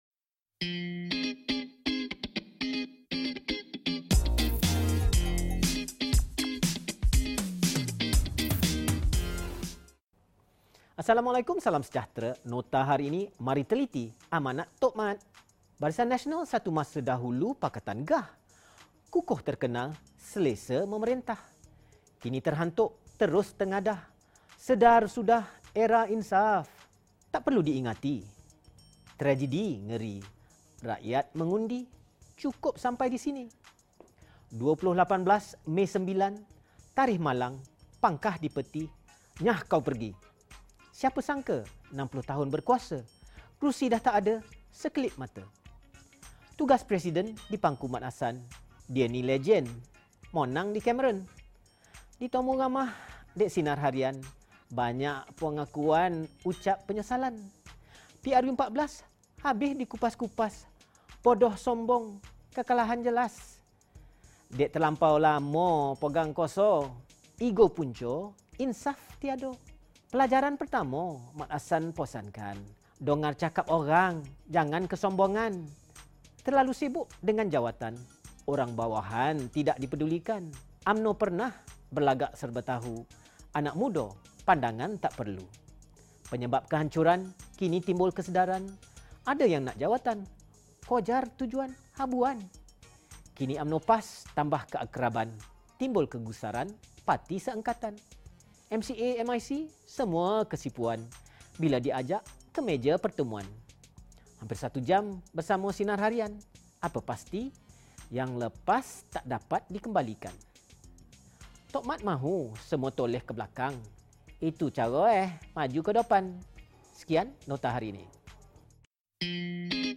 Ulasan berita